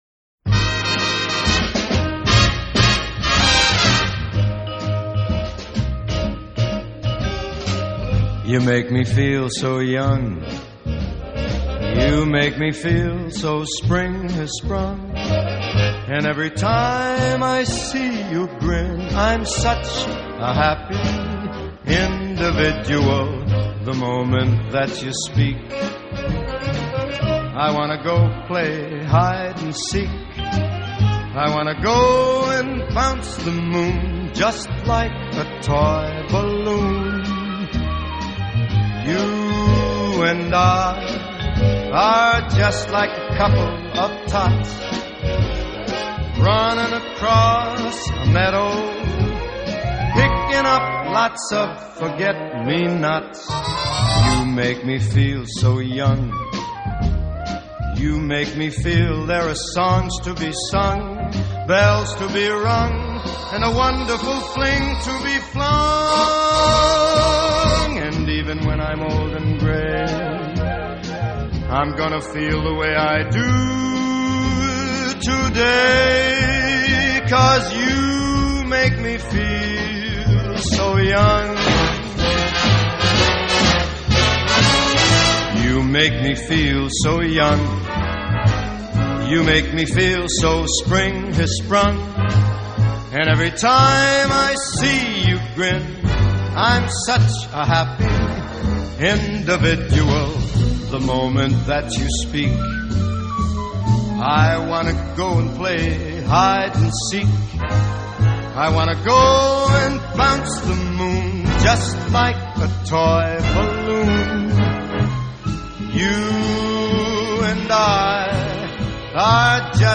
Vocal Jazz, Swing, Traditional Pop